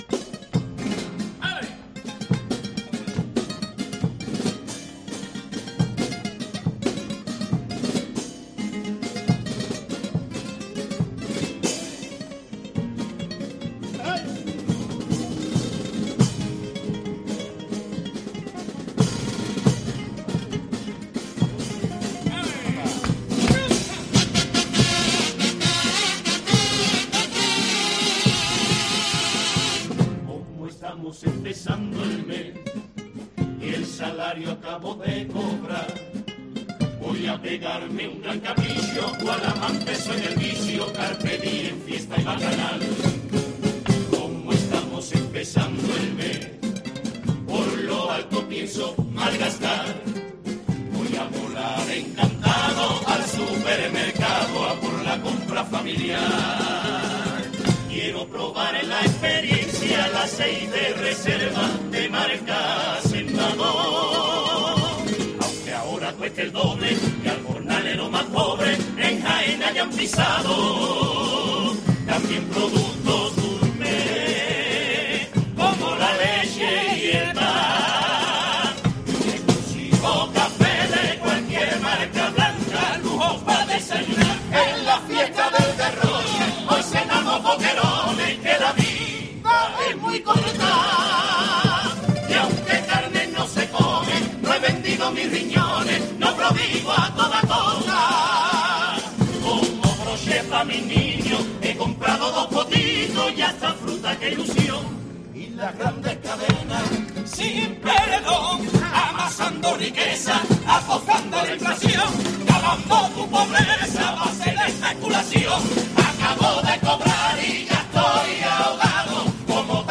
El pasodoble de 'Los trampucheros' a la inflacción
Carnaval